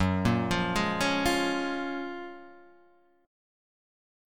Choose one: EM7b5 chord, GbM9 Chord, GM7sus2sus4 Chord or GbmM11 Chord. GbM9 Chord